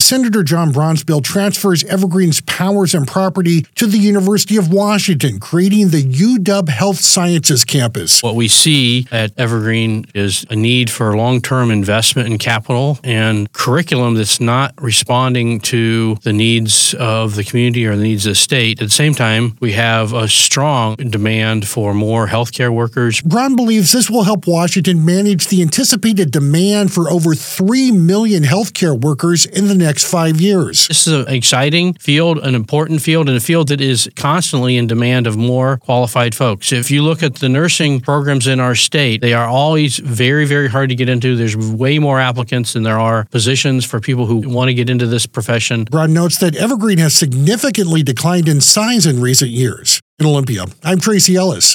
AUDIO: Braun proposes transforming The Evergreen State College to address Washington’s growing health-care workforce crisis - Senate Republican Caucus